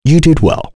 Kain-Vox_Happy5.wav